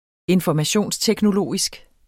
Udtale [ enfɒmaˈɕoˀnstεgnoˌloˀisg ]